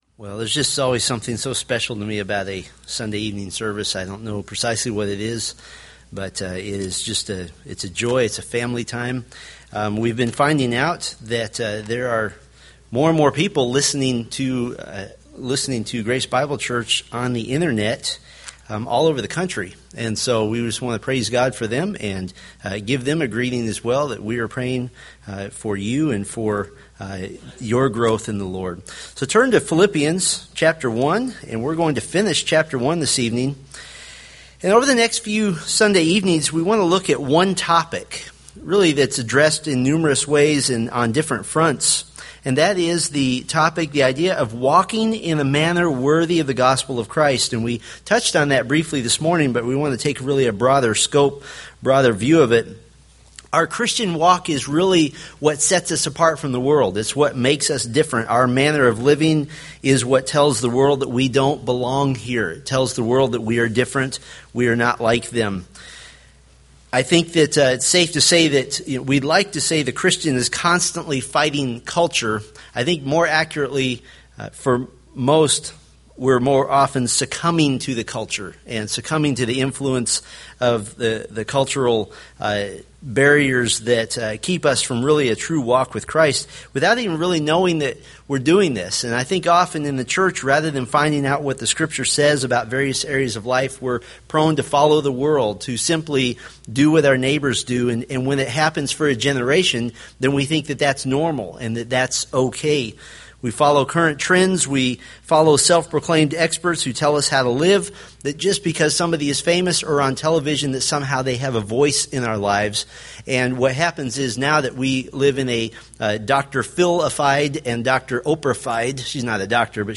From the Philippians sermon series.